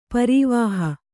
♪ parīvāha